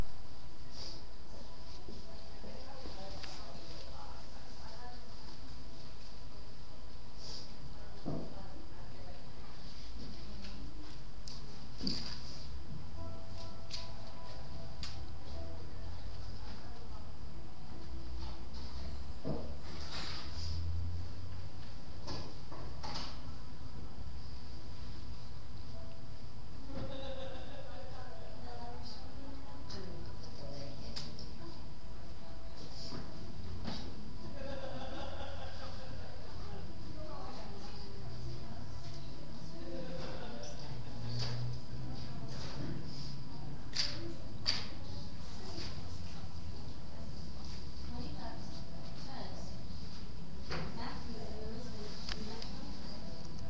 Field Recording 6
SOUND CLIP: Office of Admission LOCATION: Office of Admission SOUNDS HEARD: people talking, music playing, footsteps, sniffling, papers shuffling, doors opening, laughing